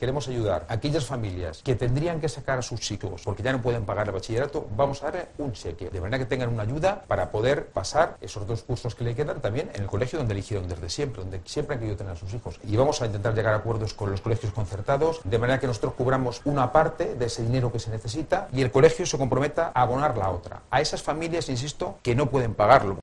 El presidente de la Comunidad de Madrid, Angel Garrido, explica las razones de esta ayuda